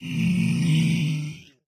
zpigdeath.ogg